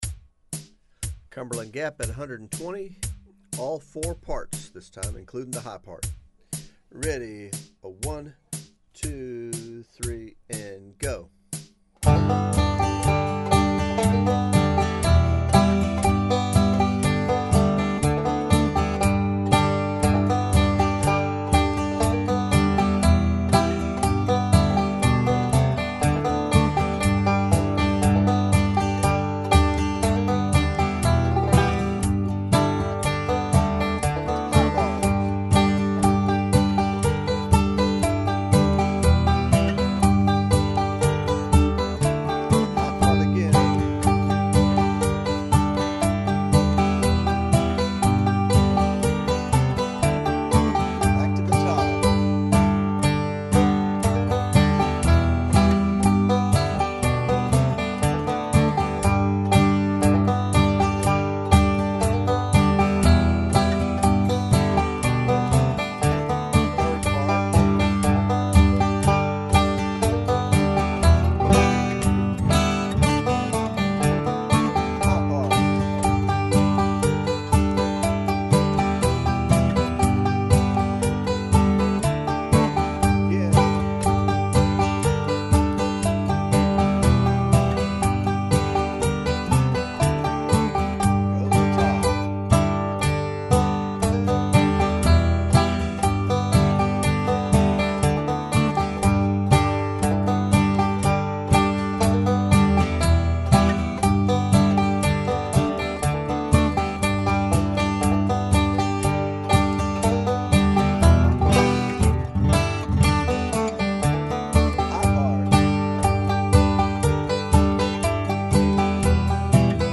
Each song is recorded at different speeds, ranging from slow to moderate, and repeats several times. Also, the banjo and guitar tracks are isolated on each side, so either one can be silenced by adjusting the balance all the way to the other side.
cumberland gap at 120 bpm foggy mtn. at 160 bpm